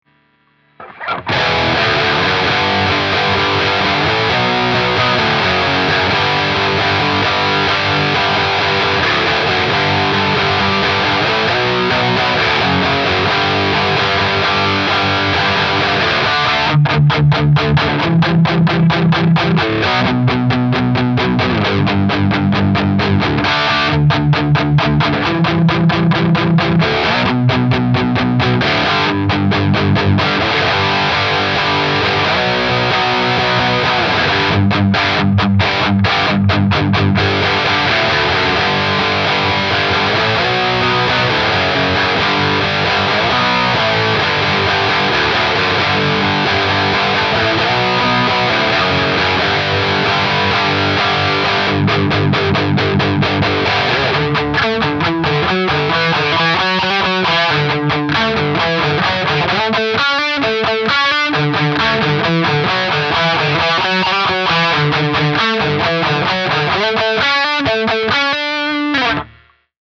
Help Recording DI Distorted Guitar Tones
Every guitar tone I record is muddy and horrible.
I have an Ibanez AZ47P1QM that I plug into a Scarlett Solo's 2nd input with the "inst" button enabled, running Logic with neural DSP digital amp...
It just always comes out sounding very strange to me.
Just sort of fuzzy, compressed, low quality-ish.